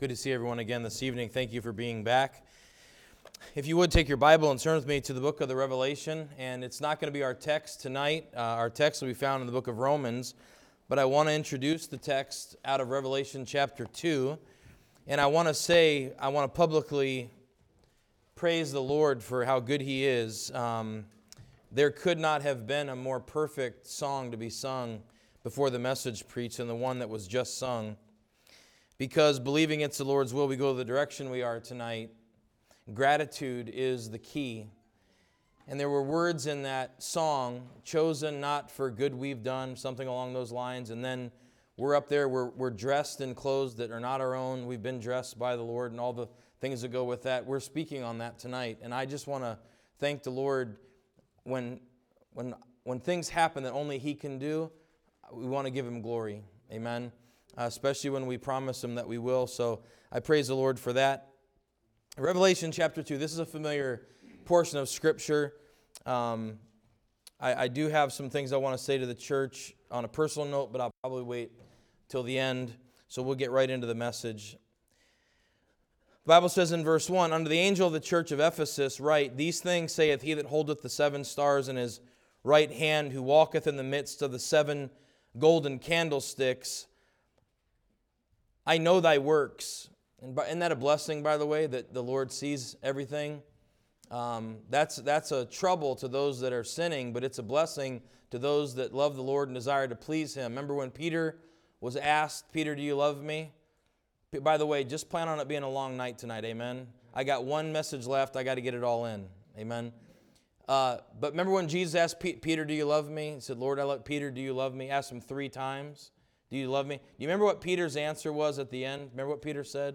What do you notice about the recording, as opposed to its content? Service Type: Winter Conference